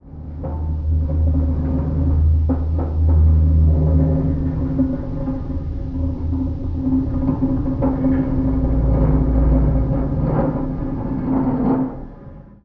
metal_low_creaking_ship_structure_02.wav